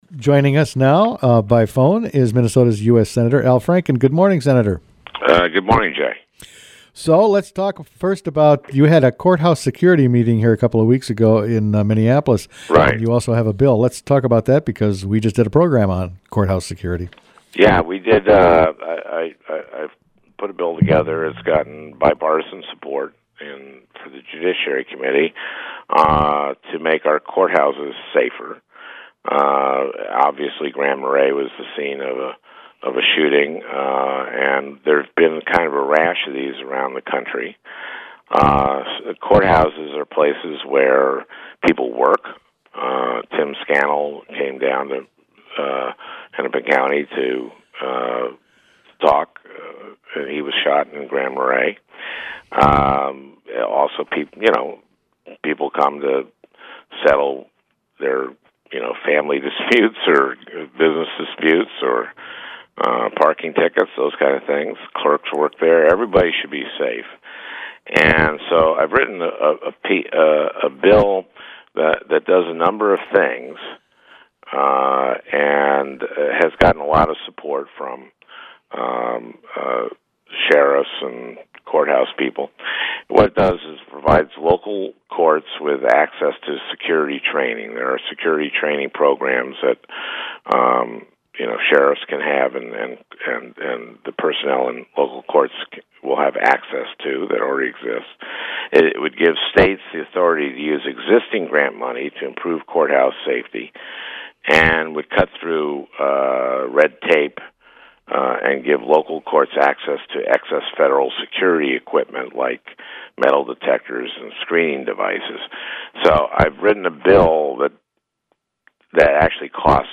Here’s that interview.